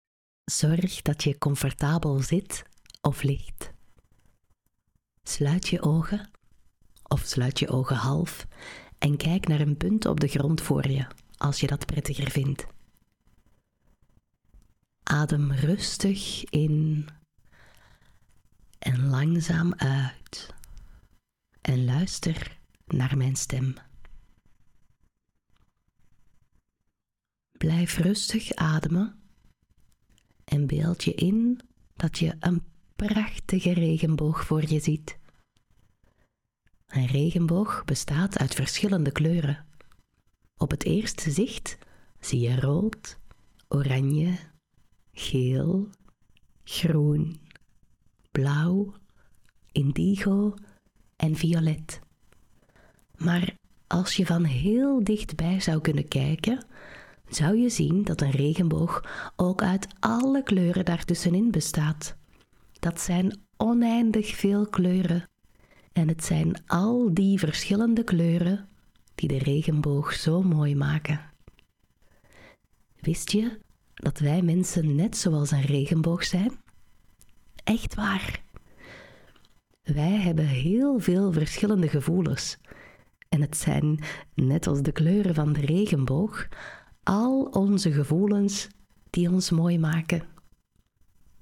Regenboog-mediatie-preview.mp3